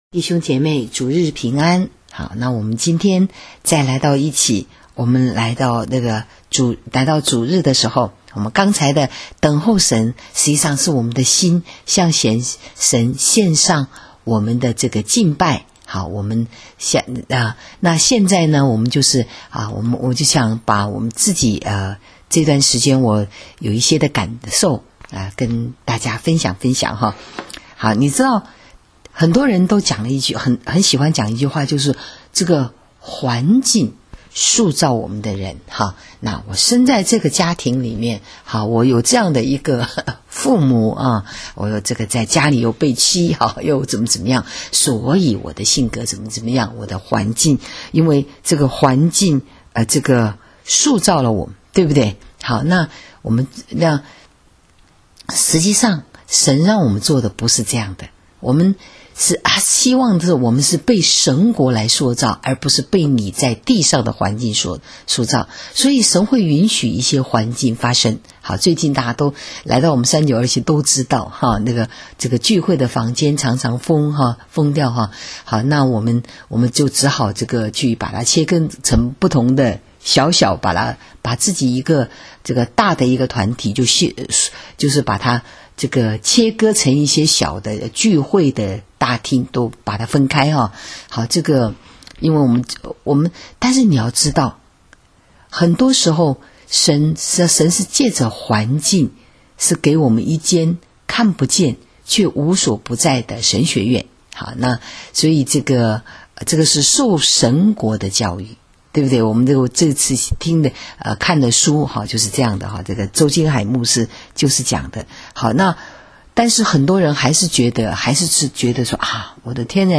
【主日信息】你让环境塑造了你吗？ （1-19-20）